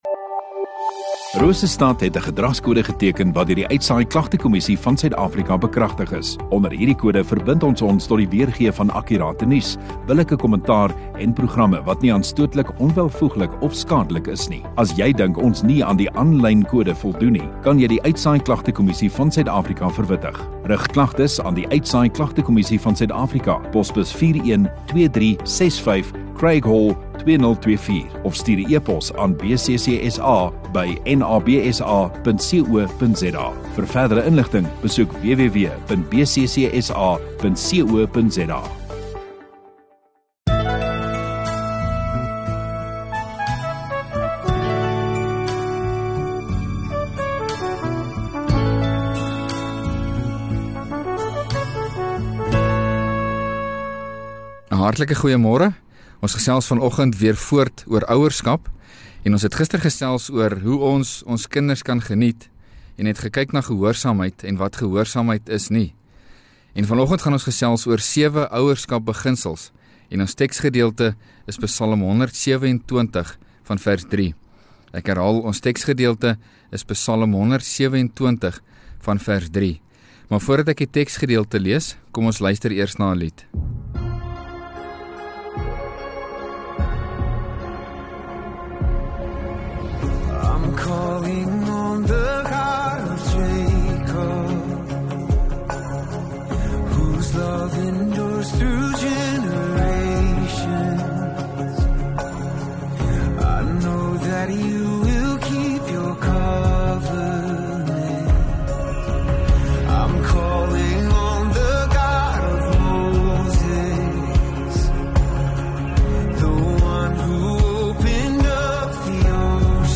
Rosestad Godsdiens 1 Feb Saterdag Oggenddiens